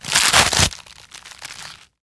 web_break.wav